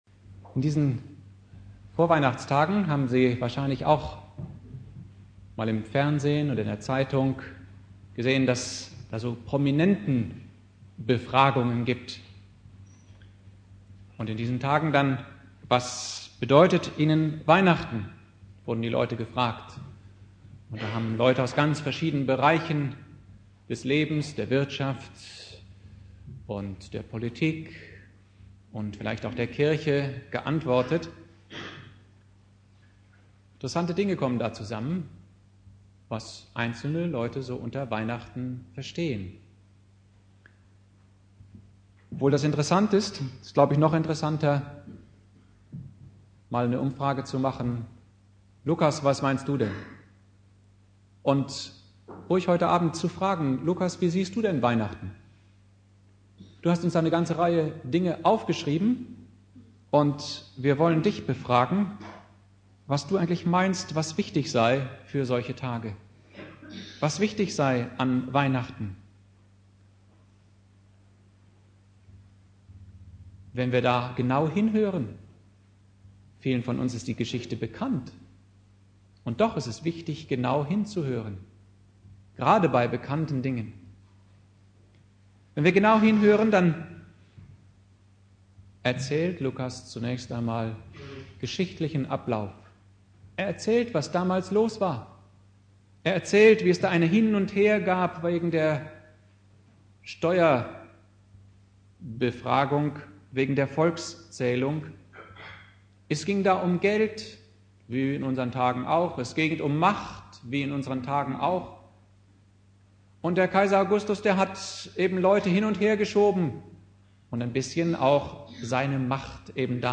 Predigt
Heiligabend